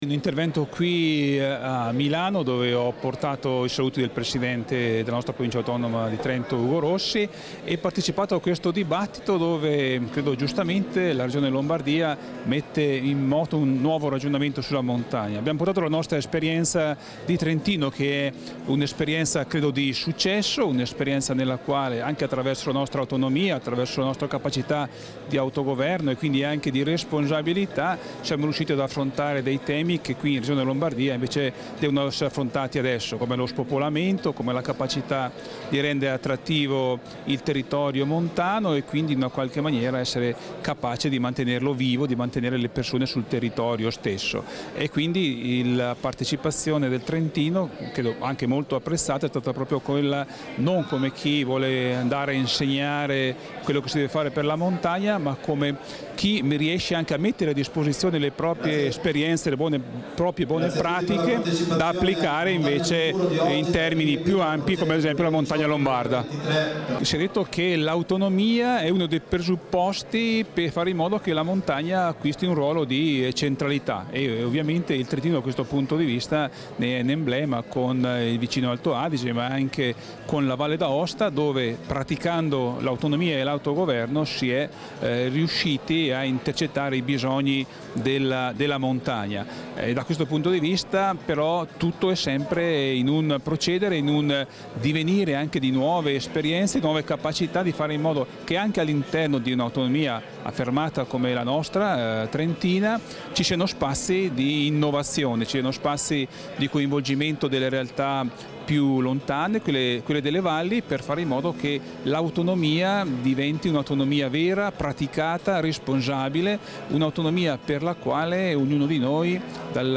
intervista_Daldoss_22_Novembre.mp3